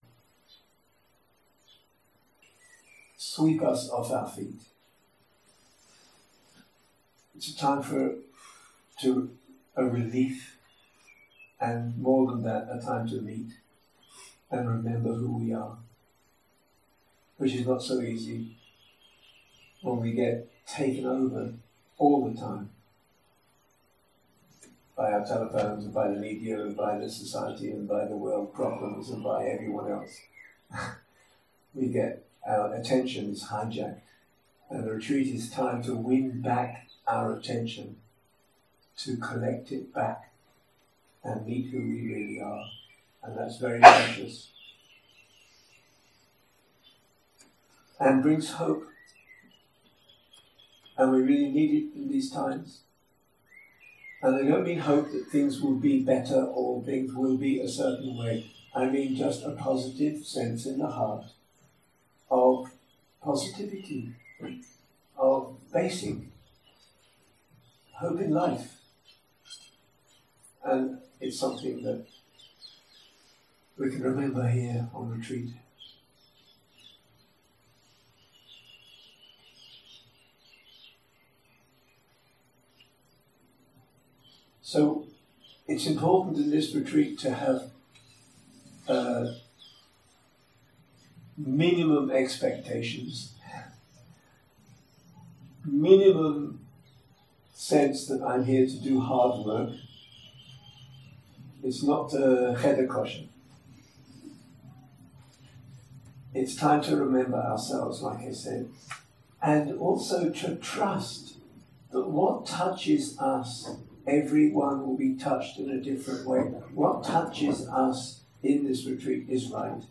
יום 1 - הקלטה 1 - ערב - שיחת פתיחה - Sacred rest & pure presence 0:00 0:00 סוג ההקלטה: Dharma type: Opening talk שפת ההקלטה: Dharma talk language: Hebrew